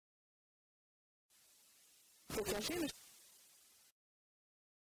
uitspraak Potager